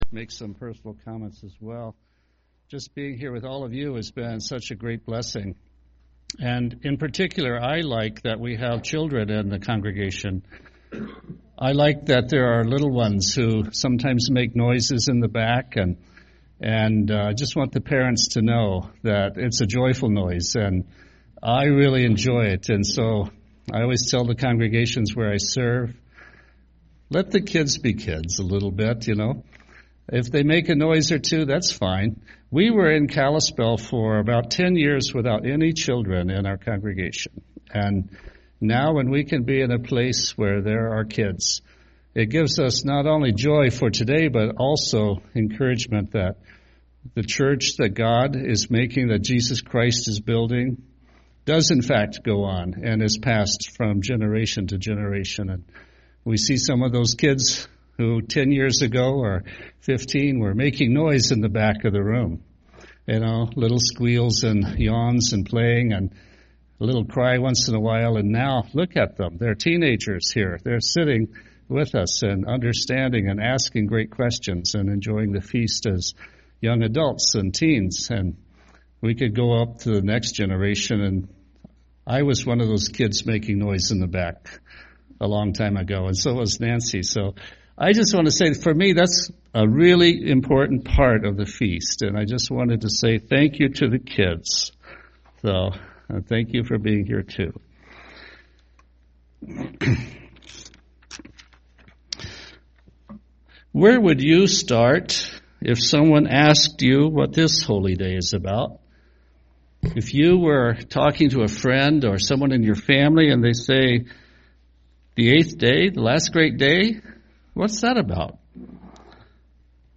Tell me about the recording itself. This sermon was given at the Bigfork, Montana 2014 Feast site.